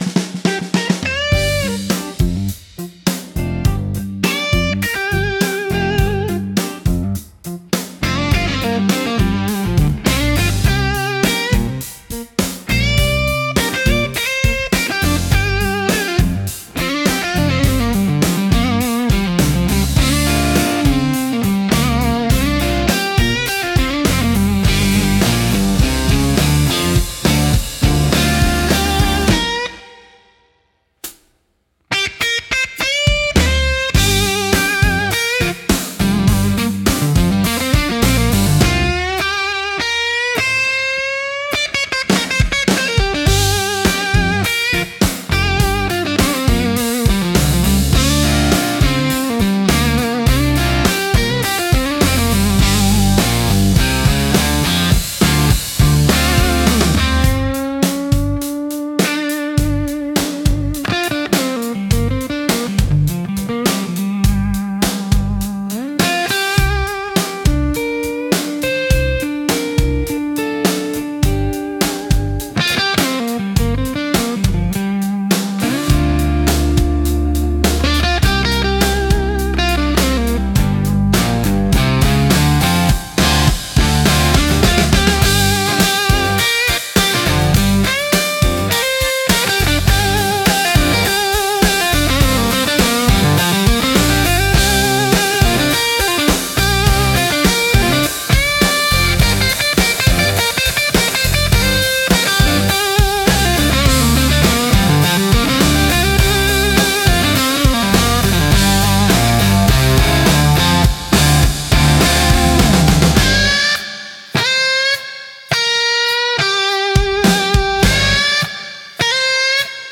ギターやハーモニカによる哀愁のあるメロディとリズムが、深い感情表現を生み出します。
しっとりとした雰囲気を求める場面で活用されるジャンルです。